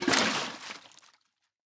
Minecraft Version Minecraft Version 25w18a Latest Release | Latest Snapshot 25w18a / assets / minecraft / sounds / item / bucket / empty_fish3.ogg Compare With Compare With Latest Release | Latest Snapshot
empty_fish3.ogg